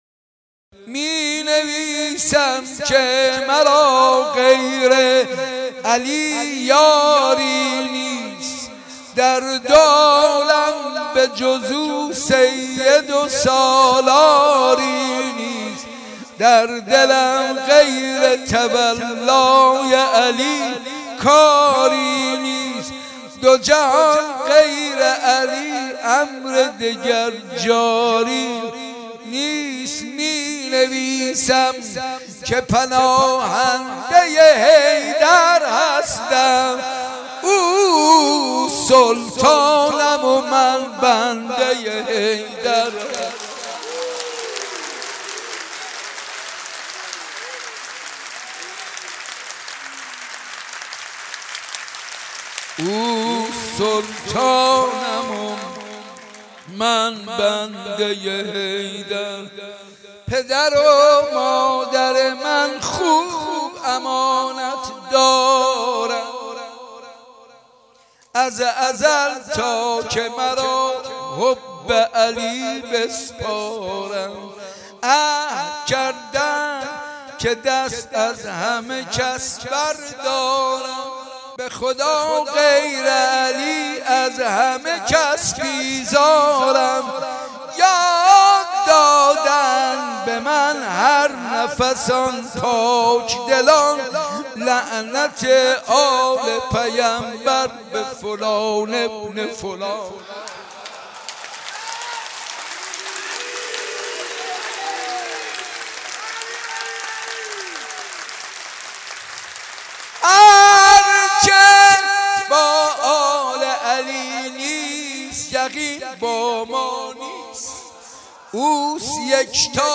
حاج منصور ارضی
حسینیه ی صنف لباس فروش ها
شعر مدح